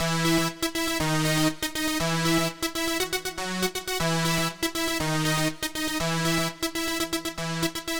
Power Pop Punk Keys 01a.wav